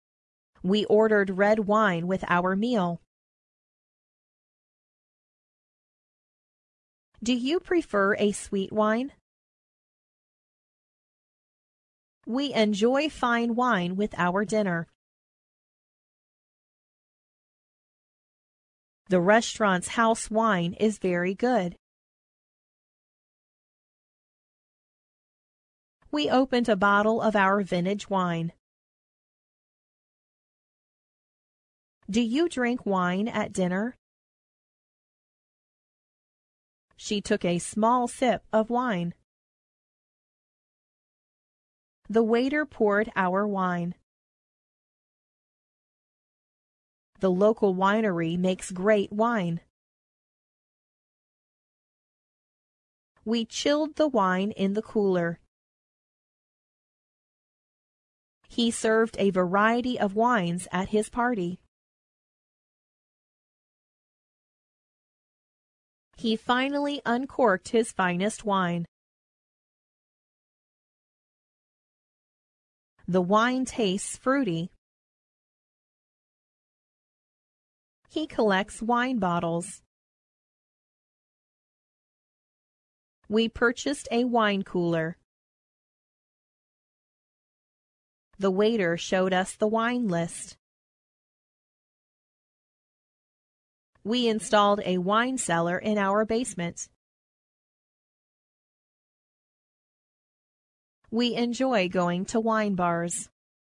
wine-pause.mp3